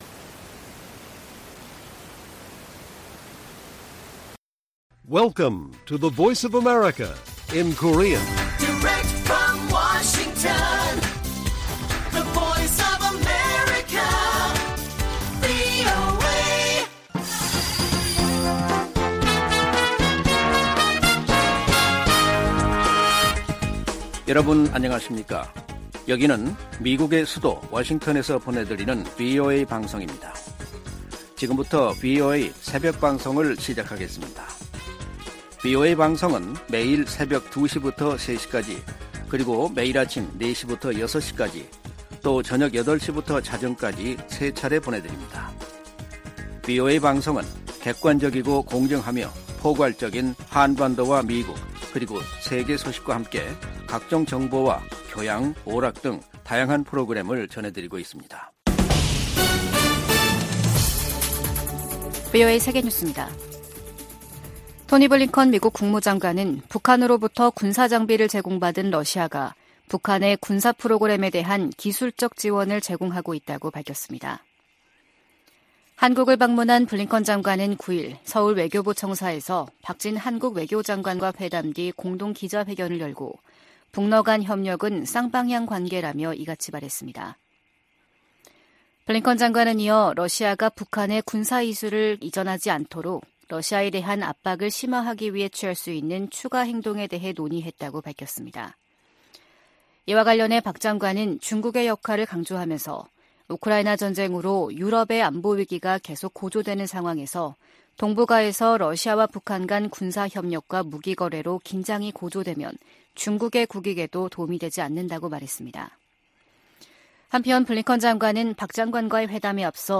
VOA 한국어 '출발 뉴스 쇼', 2023년 11월 10일 방송입니다. 토니 블링컨 미 국무장관과 박진 한국 외교장관이 9일 서울에서 만나 북러 군사협력 문제 등 현안을 논의했습니다. 백악관은 미국이 한반도 비상상황 대비를 위해 늘 노력하고 있다며, 한국의 중동 개입은 '주권적 결정' 사안이라고 강조했습니다. 주요7개국(G7) 외교장관들이 북한의 지속적인 대량살상무기 개발과 러시아로의 무기 이전을 강력히 규탄했습니다.